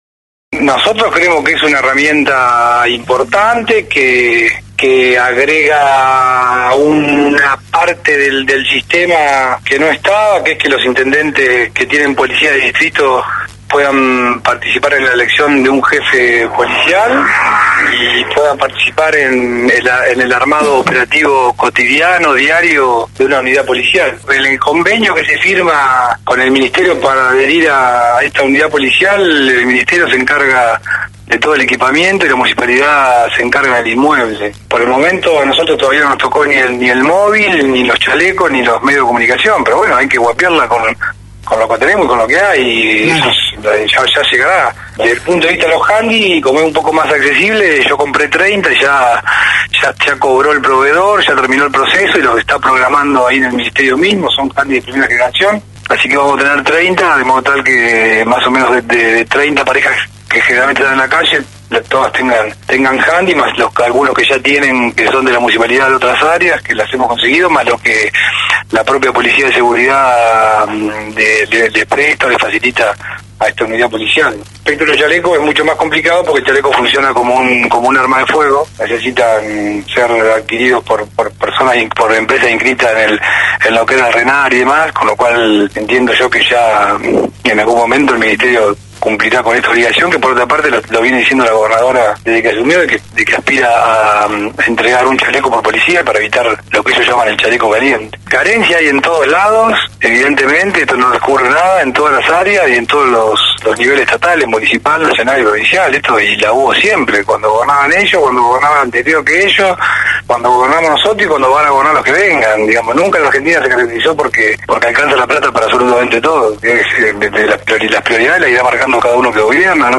Escucha el audio del funcionario , que le responde al Concejal Ramiro Llan de Rosos quien elevó un pedido de informe sobre la falta de equipamiento de la flamante policía local.